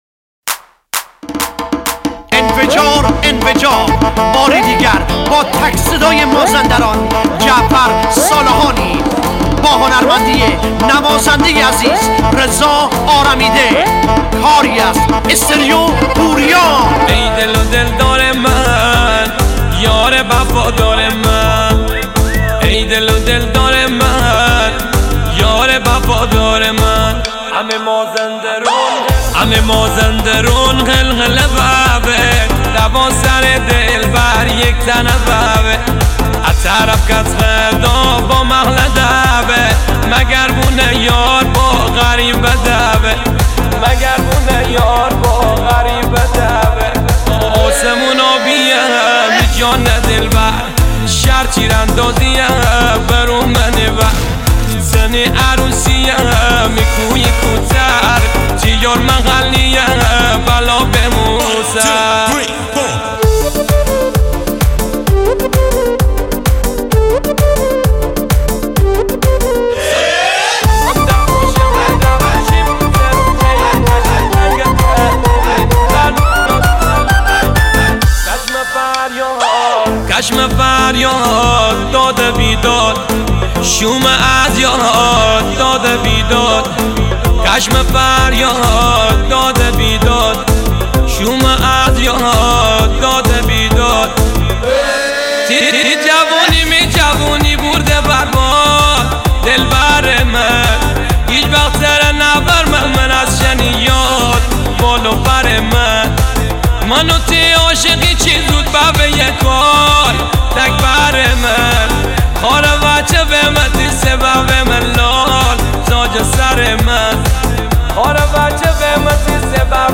Mahalli